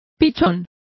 Complete with pronunciation of the translation of pigeons.